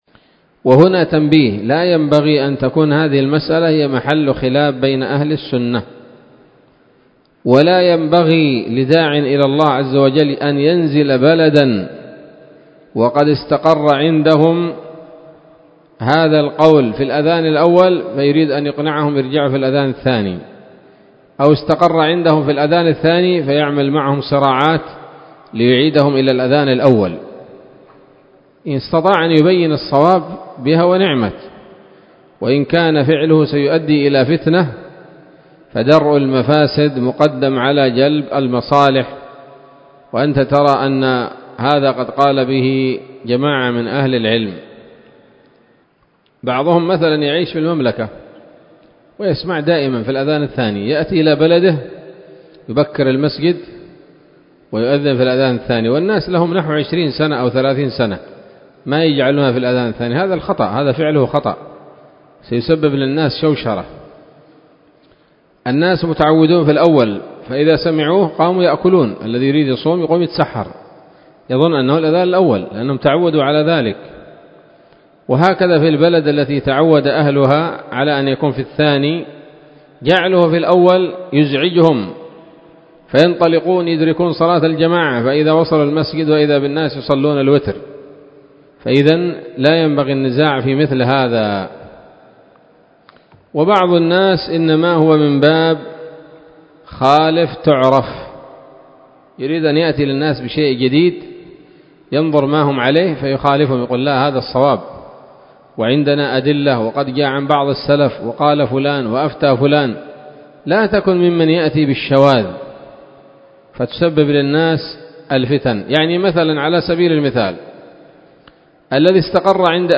تنبيه ونصيحة لطلاب العلم والدعاة في المسائل الفقهية الخلافية، والحكمة في التعامل مع المسائل الخلافية، وقد كانت ضمن درس السموط الذهبية في ذكر الخلاف حول مسألة التثويب في صلاة الفجر